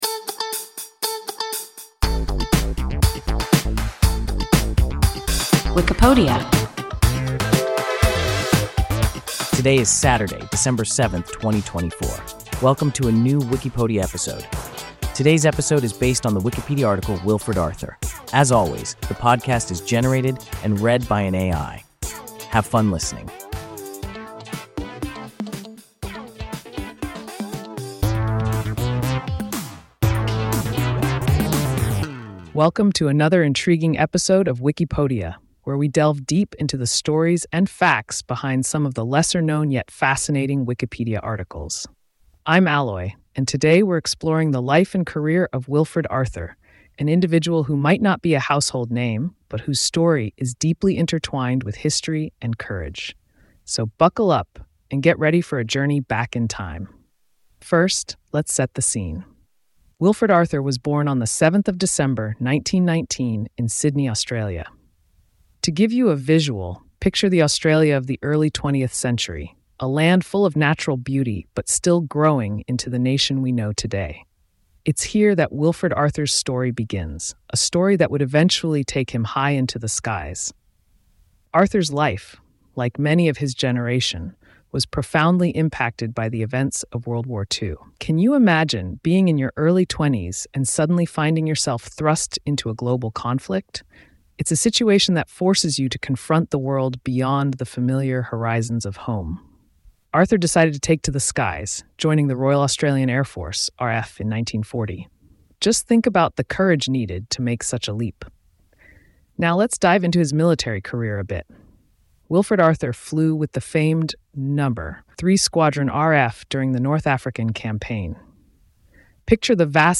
Wilfred Arthur – WIKIPODIA – ein KI Podcast